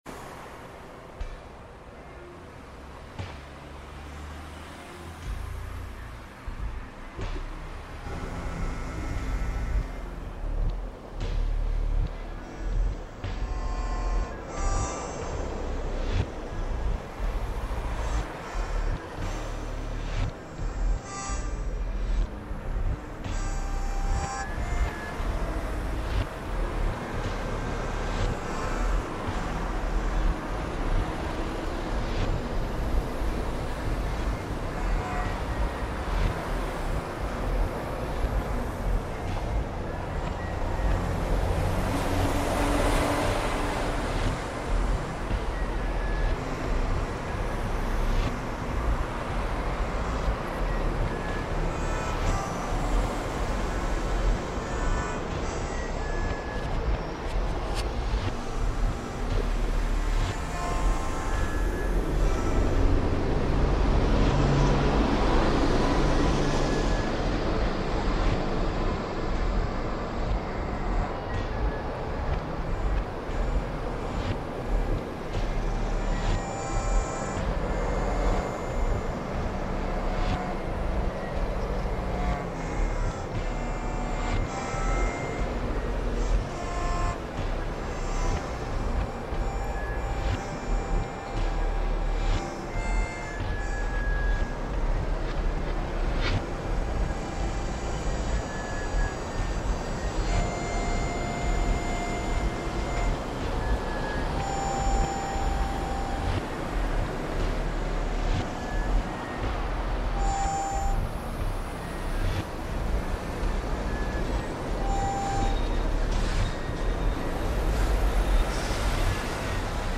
German traffic reimagined